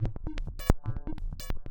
RI_ArpegiFex_140-04.wav